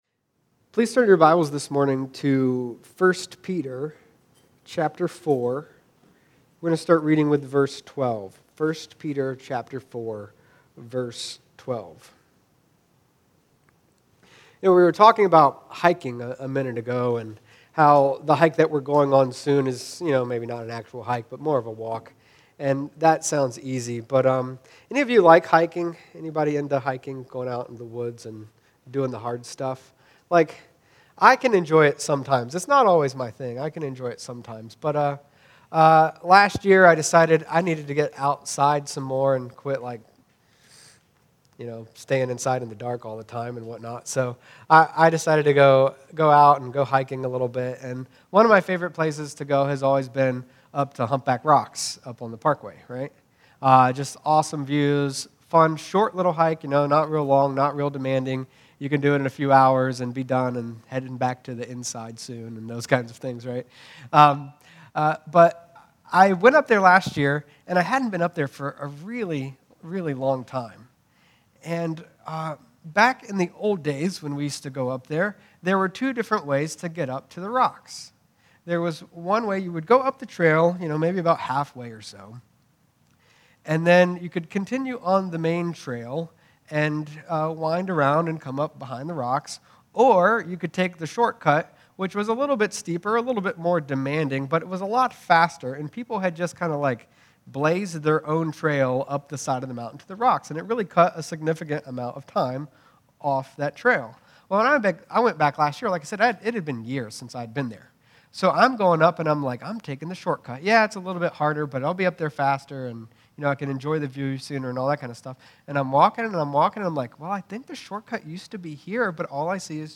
Sermons | New Life Church